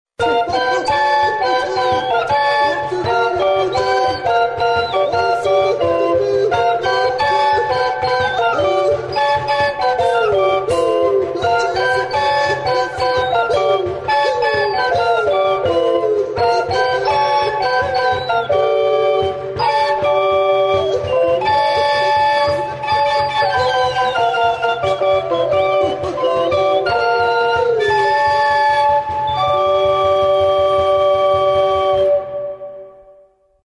Aerófonos -> Flautas -> Recta (dos manos) + kena